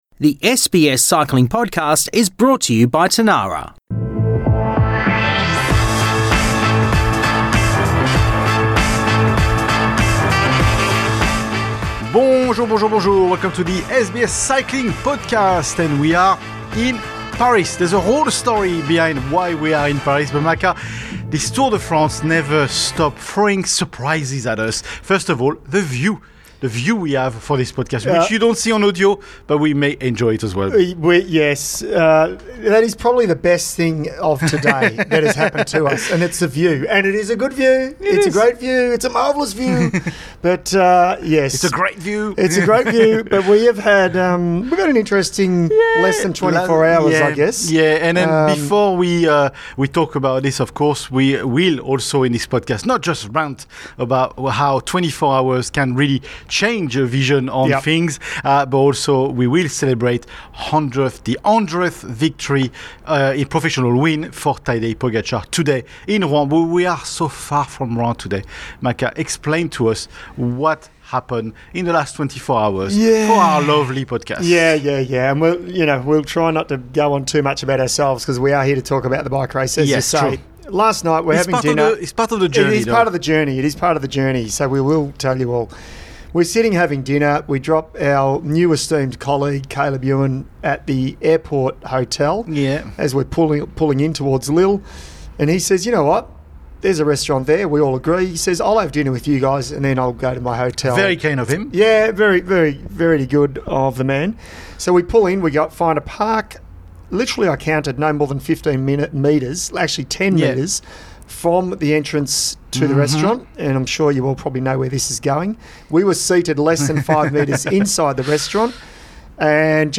Recorded from Paris, we dive into the latest twists of the Tour de France and share a few unexpected stories from behind the scenes.